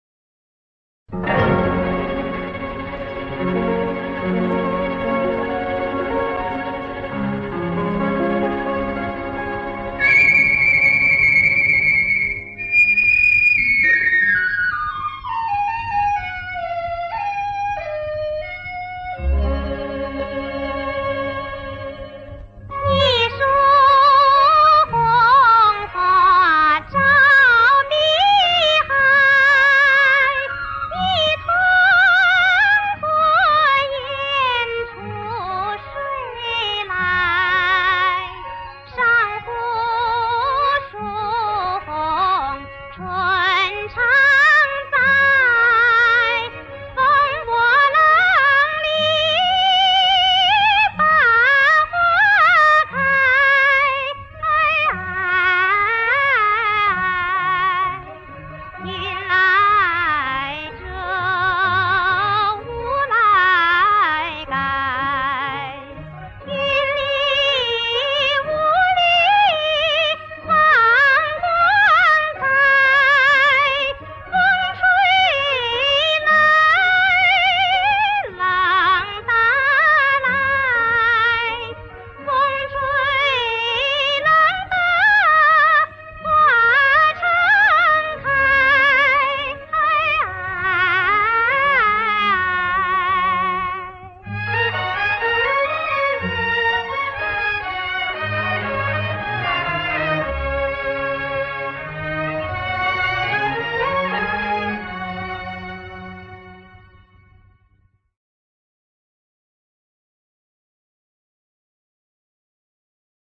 电影原声音乐